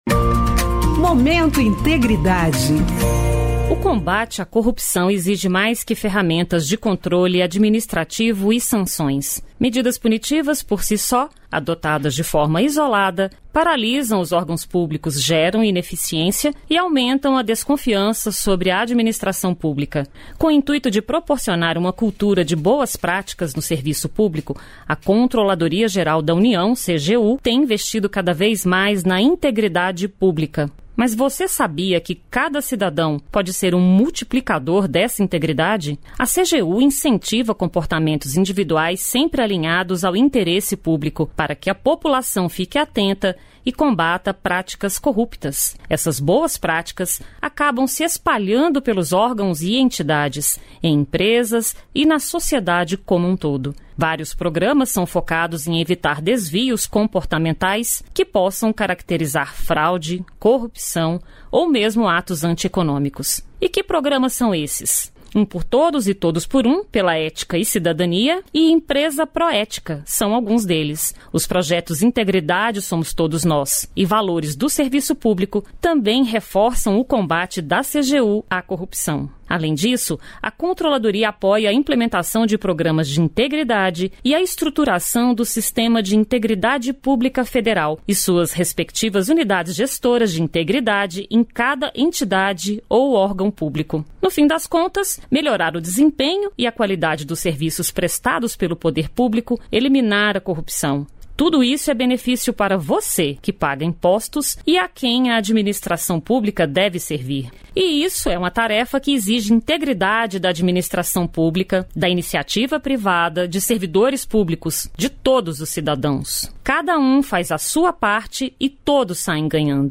Spots Informativos de Rádio